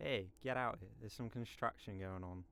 Hey get out of here theres some.wav